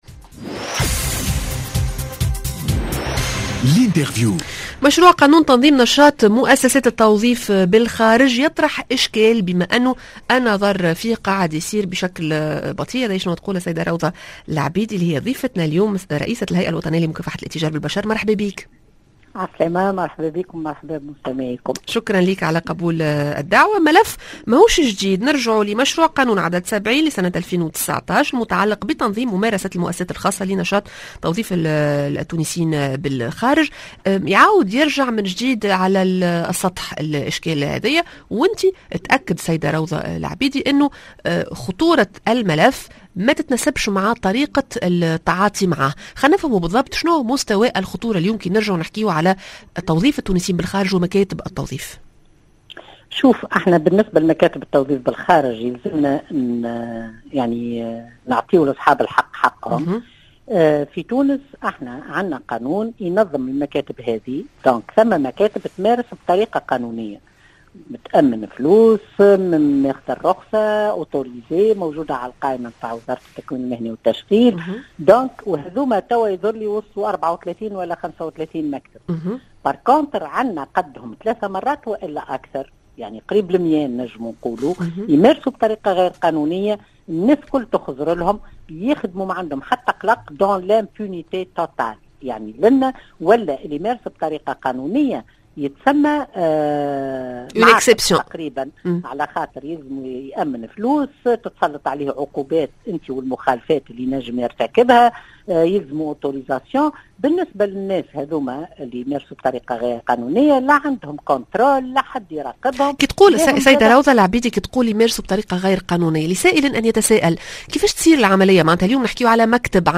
L'interview: مكاتب التشغيل بالخارج بين القانوني و التجاوزات مع روضة لعبيدي رئيسة الهيئة الوطنية لمكافحة الاتجار بالبشر